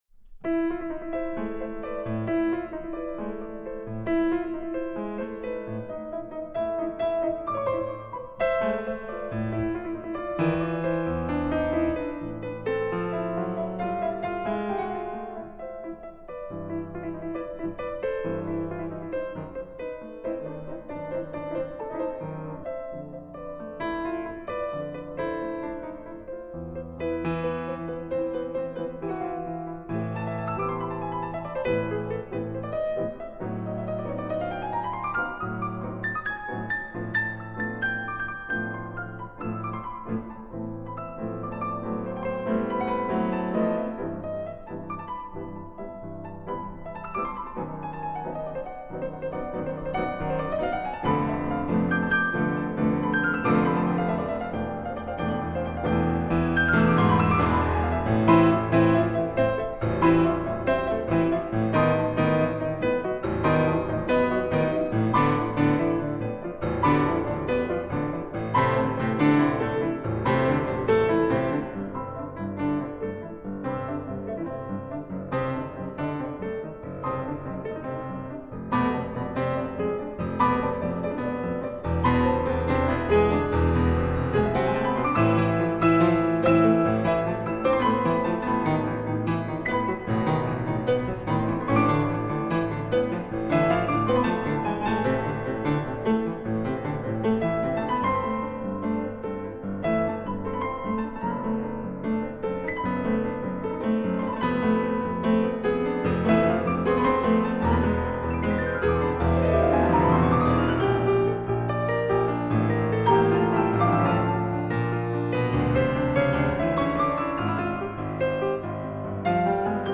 for 2 Pianos